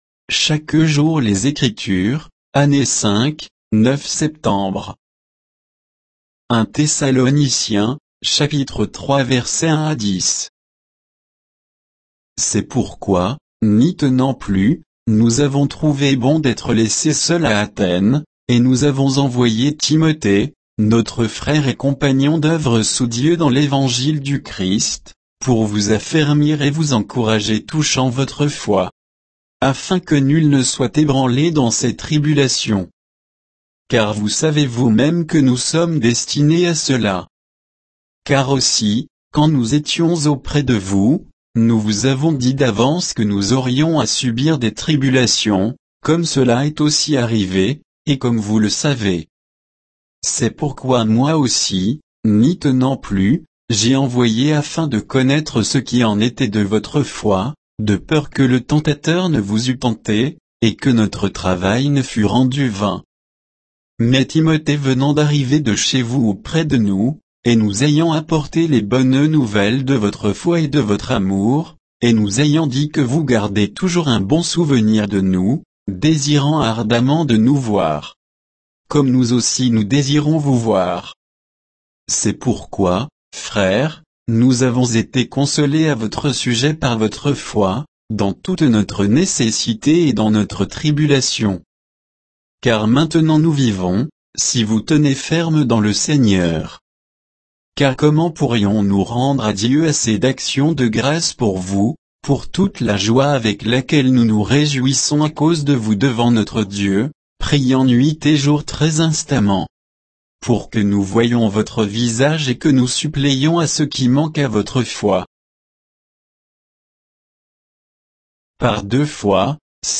Méditation quoditienne de Chaque jour les Écritures sur 1 Thessaloniciens 3